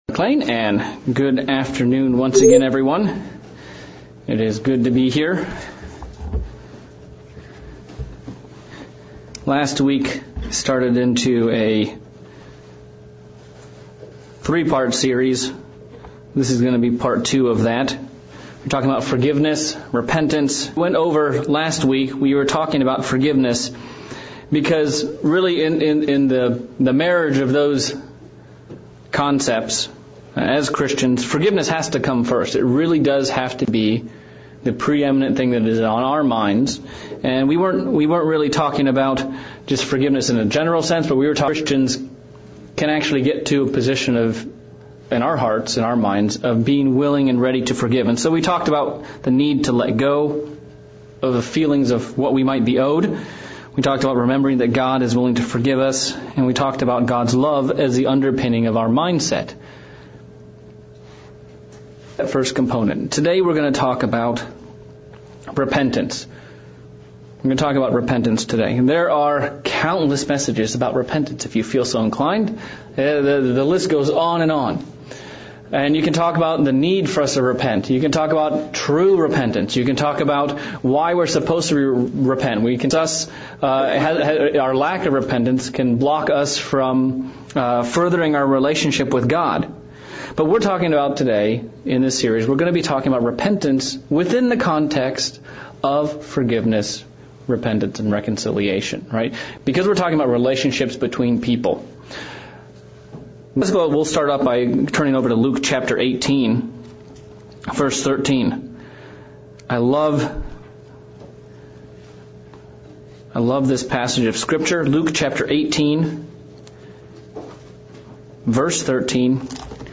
Sermon series.